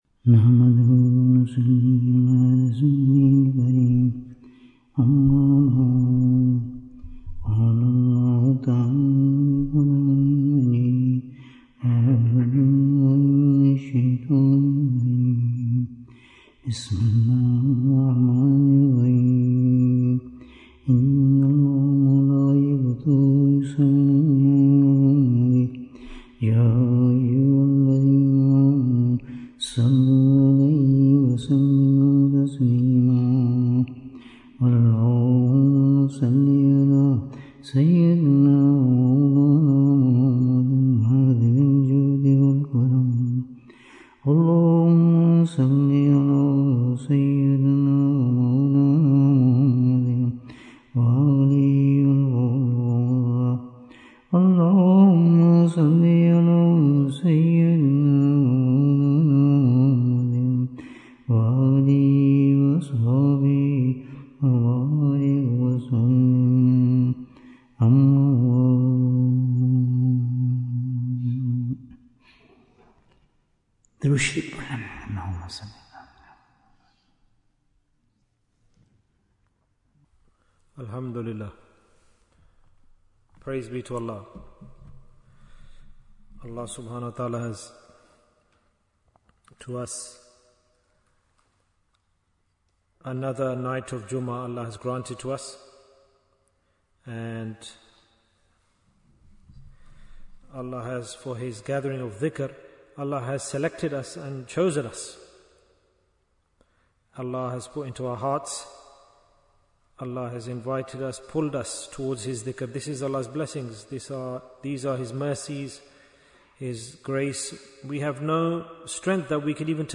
Who are the People of Jannah in this Dunya? Bayan, 85 minutes9th October, 2025